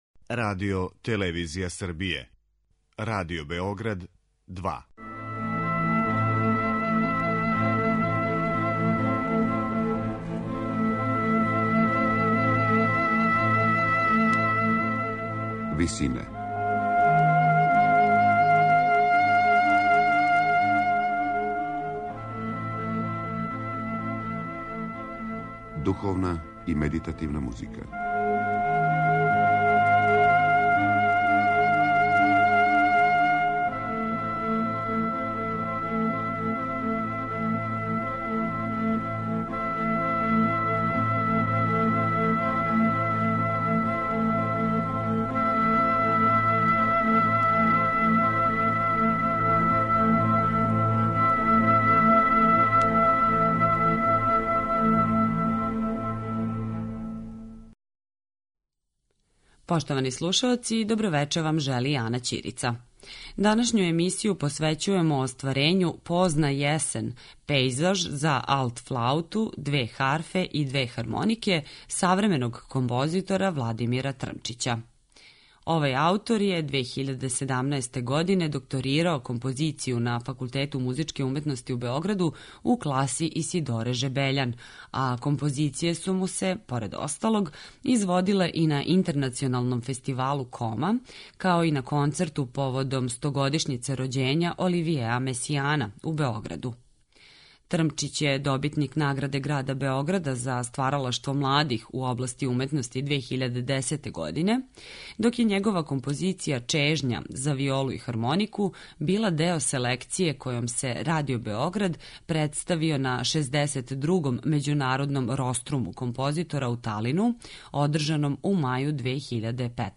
за алт-флауту, две харфе и две хармонике